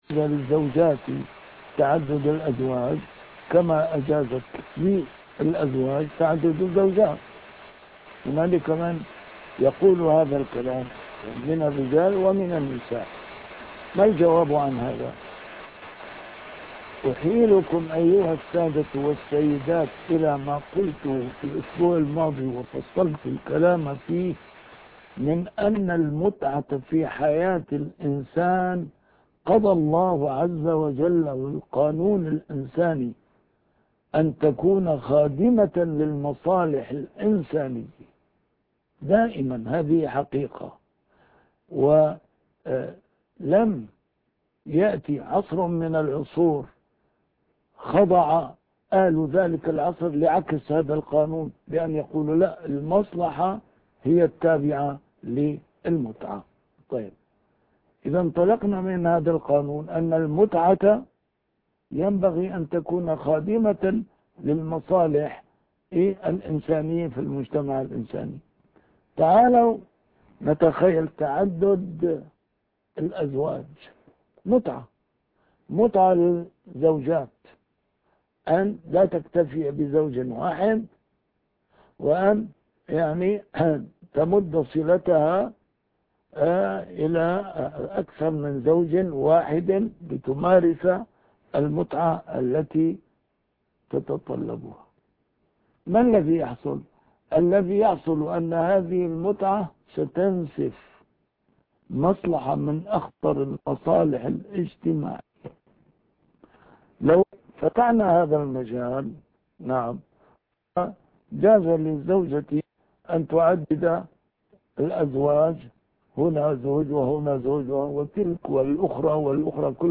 A MARTYR SCHOLAR: IMAM MUHAMMAD SAEED RAMADAN AL-BOUTI - الدروس العلمية - درسات قرآنية الجزء الثاني - 11- المرأة في القرآن الكريم